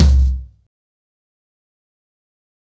InDaHouse-90BPM.43.wav